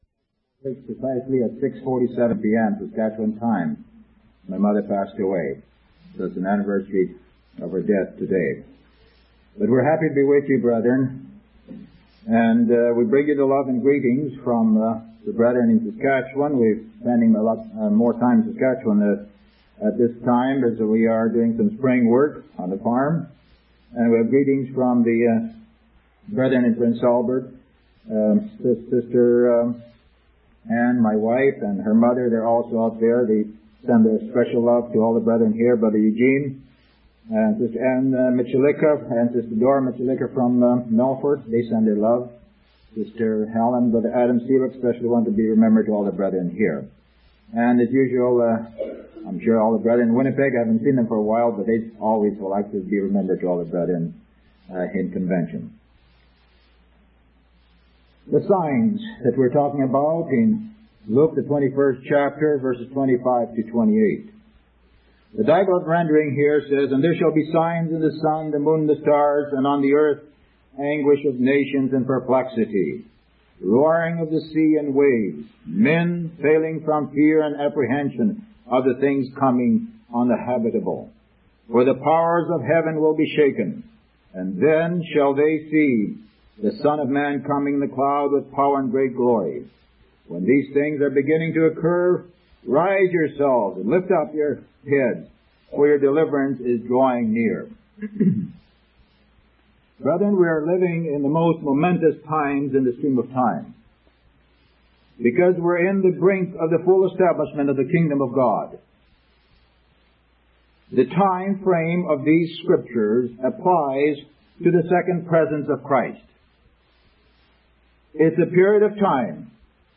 From Type: "Discourse"
Vancouver B.C. 1991